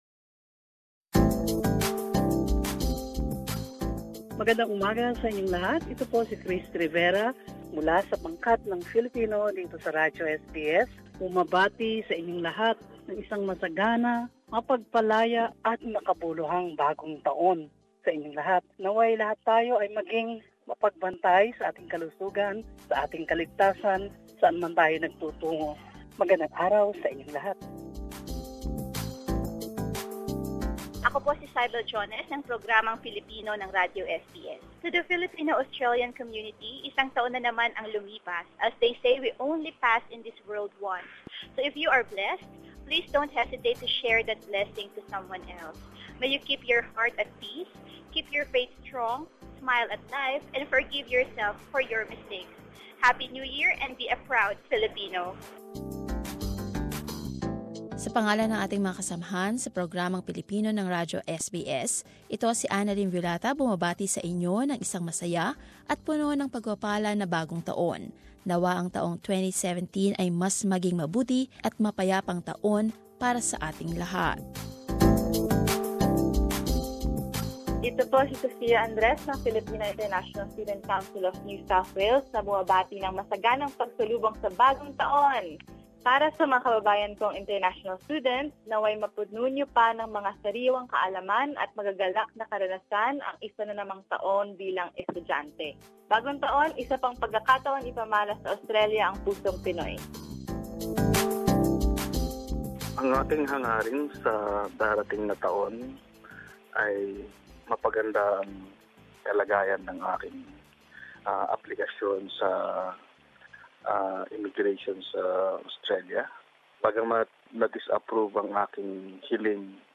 Greetings of a happy new year with full of hope and blessings from few members of the Filipino community and from some members of the Filipino program of SBS Radio.